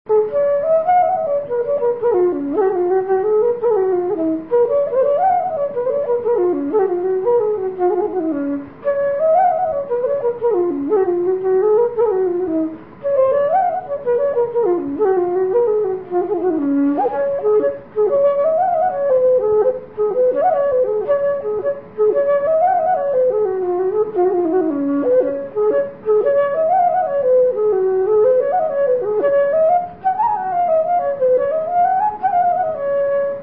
Here are a few short folk melodies I have composed.
A jig